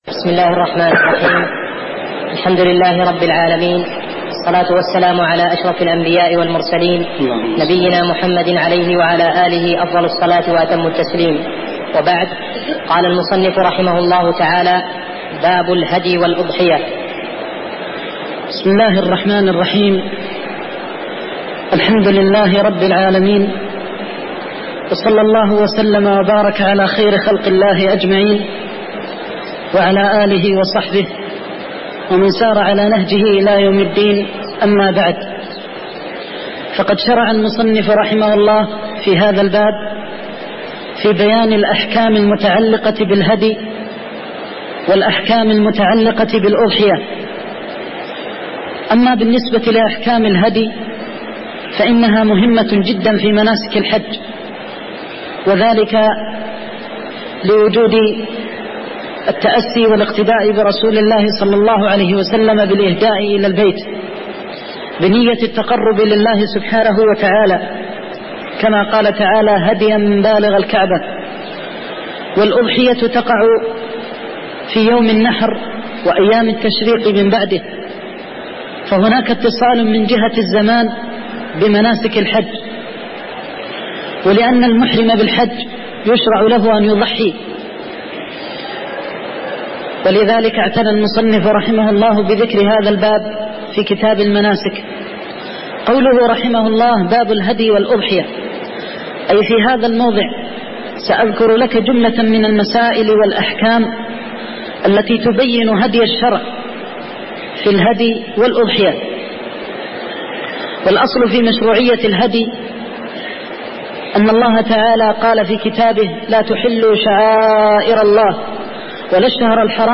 تاريخ النشر ١٨ ذو القعدة ١٤١٧ هـ المكان: المسجد النبوي الشيخ